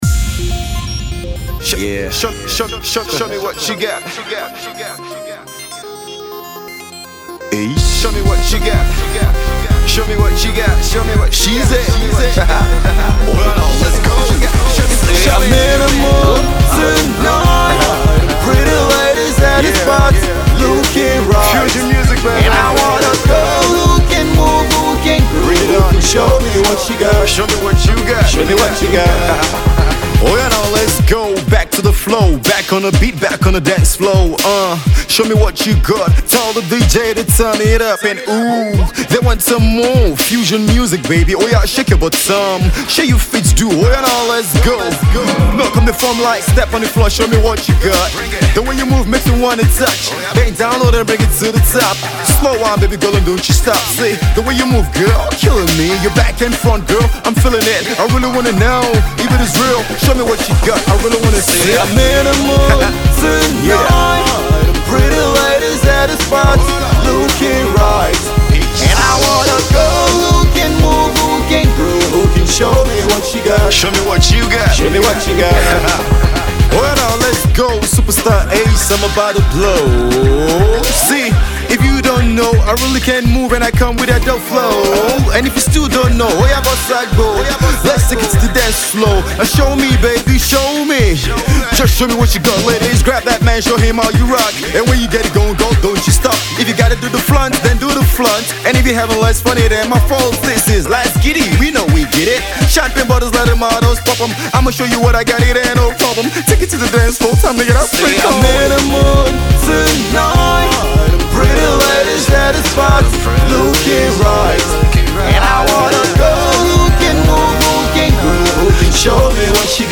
Afro-Dance tunes for the Nigerian club dance floor